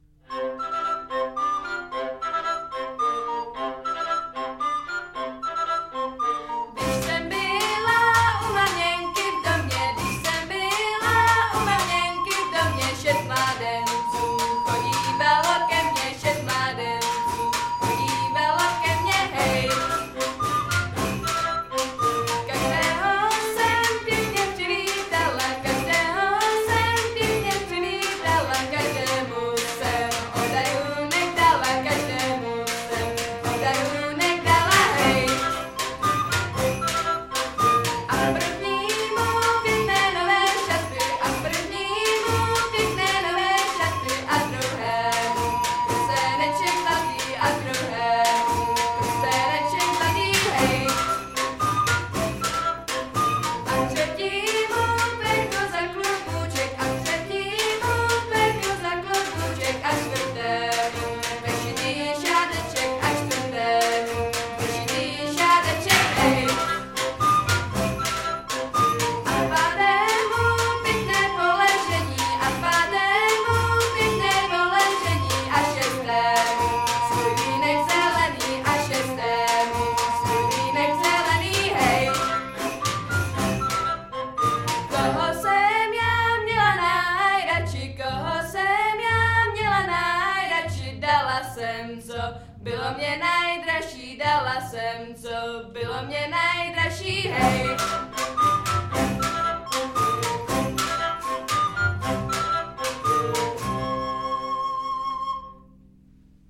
Nahrávali jsme ve Spálově v ZUŠce na jaře 2019.